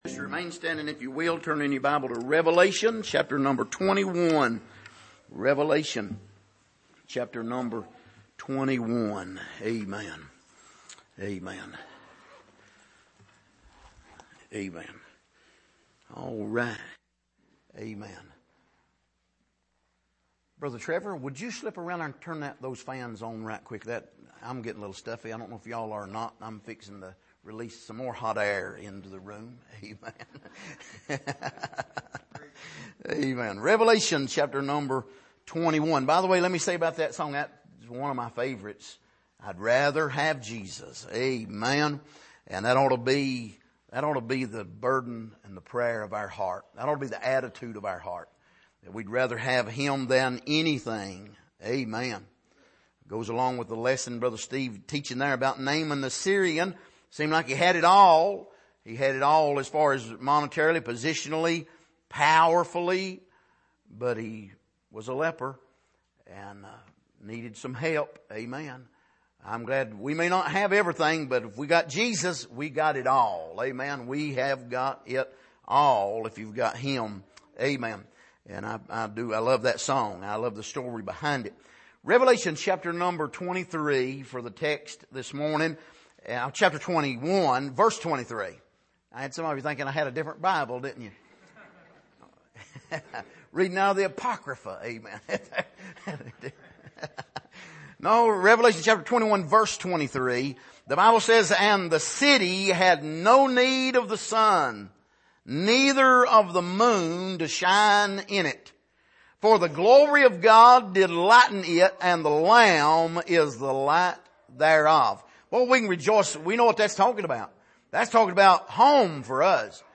Passage: Revelation 21:23-27 Service: Sunday Morning Are You In The Book?